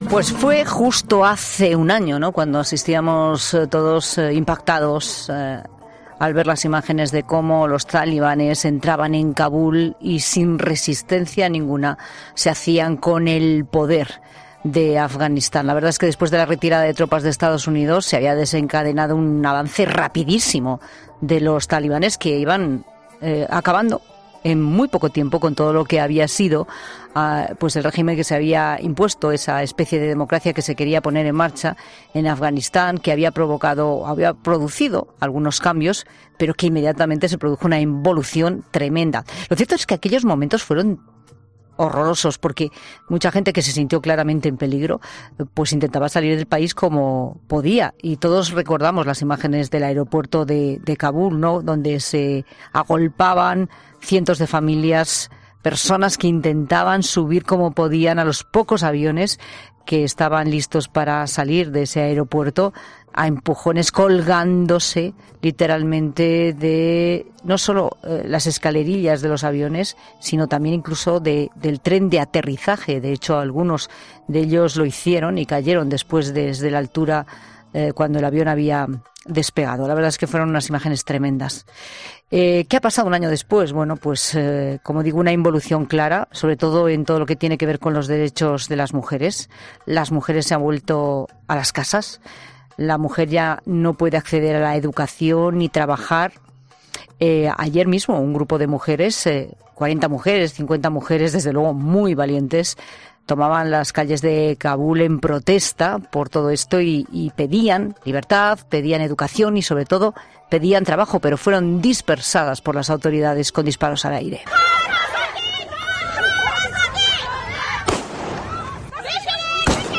AUDIO: Varios afganos evacuados a España recuerdan en 'La Tarde' cómo vivieron aquella situación además de explicar cómo es su nueva vida en España